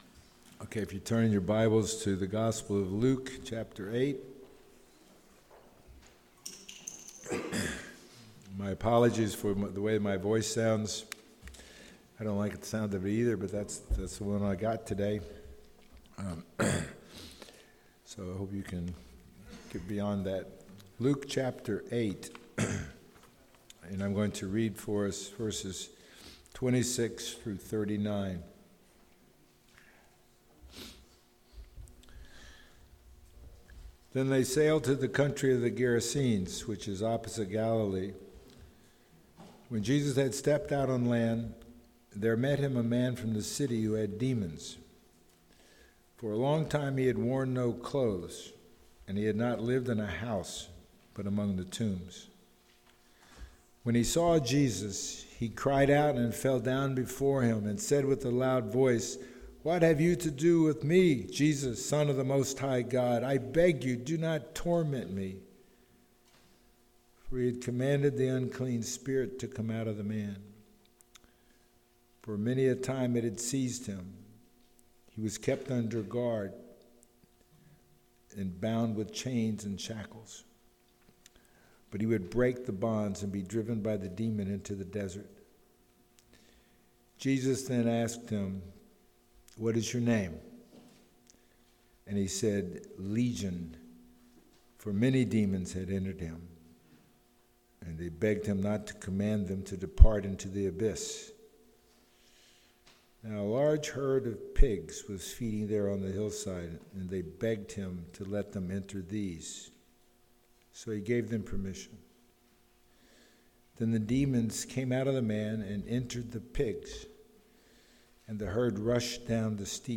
Passage: Luke 8:26-39 Service Type: Sunday Morning